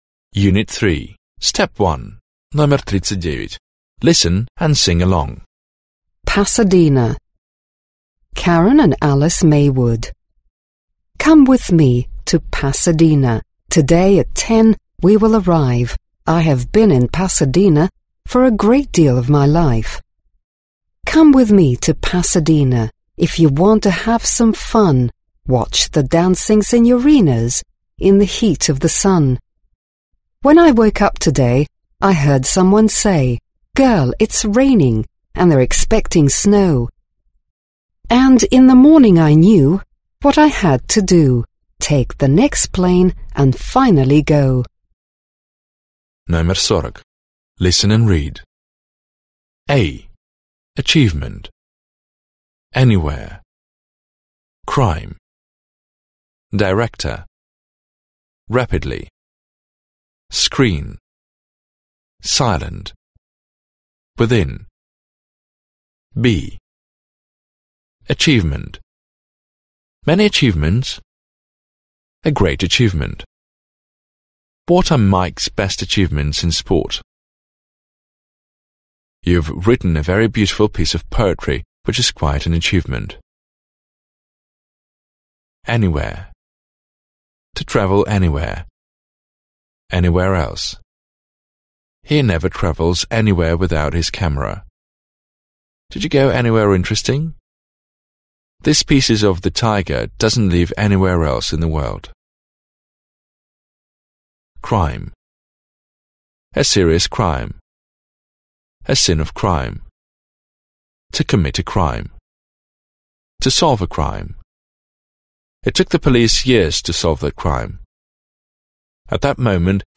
Аудиокнига Английский язык. 8 класс. Аудиоприложение к учебнику часть 2 | Библиотека аудиокниг